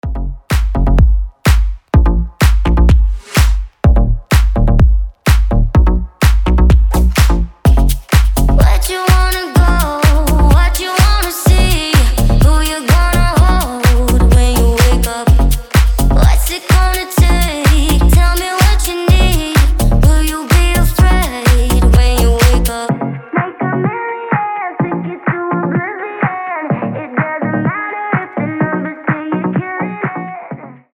• Качество: 320, Stereo
женский вокал
Стиль: deep house, future house